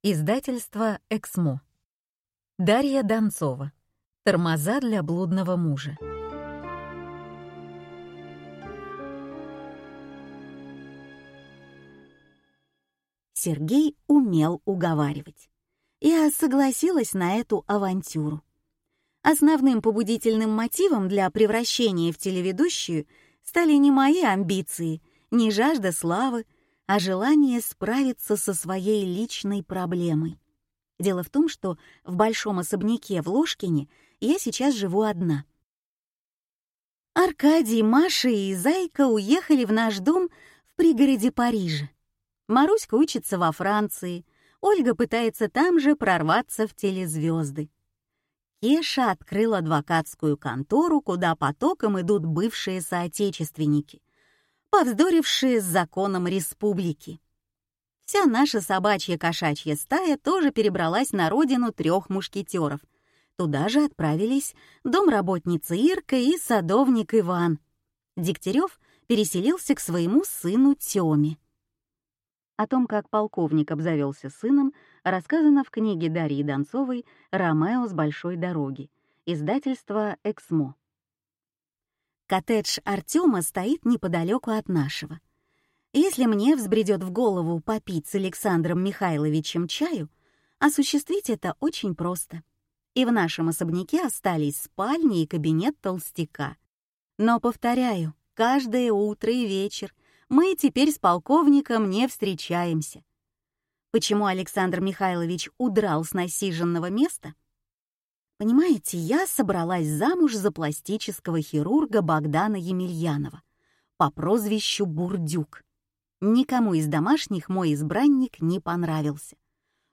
Аудиокнига Тормоза для блудного мужа | Библиотека аудиокниг